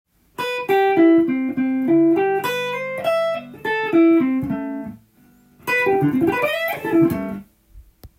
スィープ系フレーズです。
派手な感じも上手そうに聞こえて良いですね！